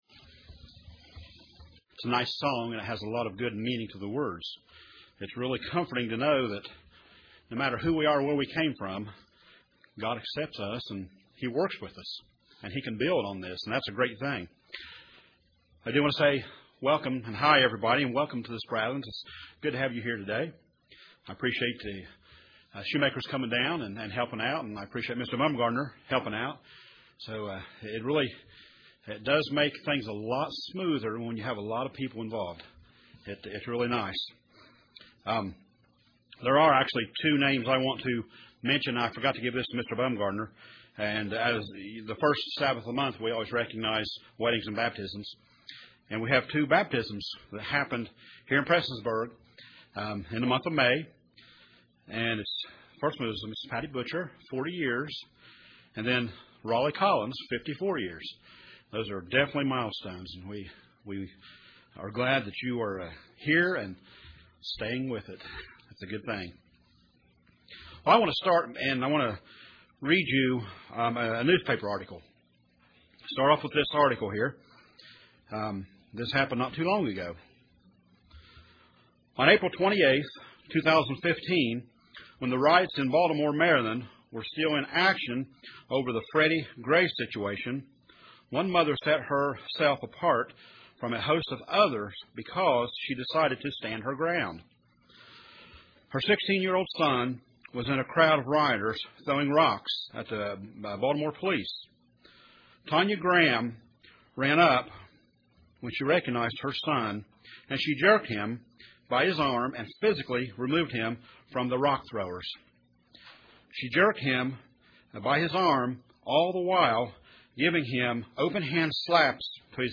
Sermons
Given in Paintsville, KY